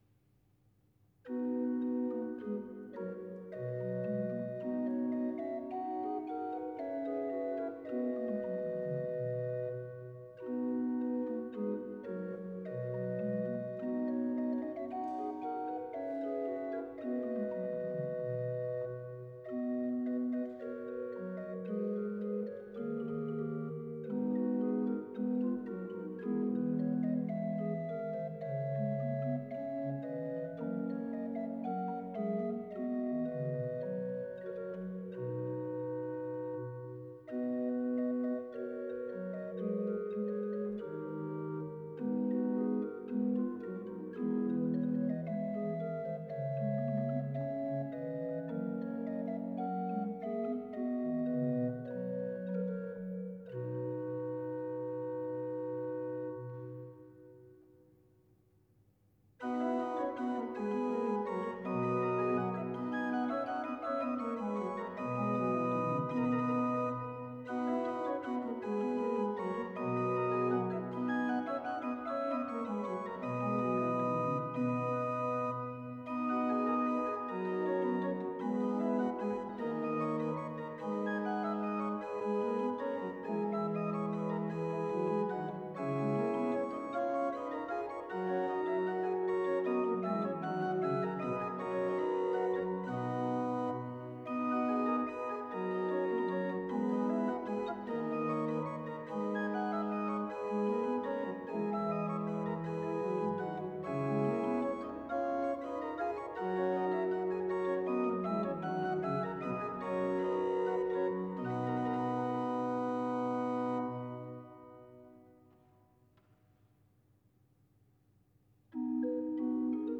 Organ
From a concert of Italian music performed in St Scholastica's, Sydney, broadcast by the ABC (Australian Broadcasting Corporation). The organ for this piece is a chamber organ by Klopp tuned for this concert in quarter-comma meantone.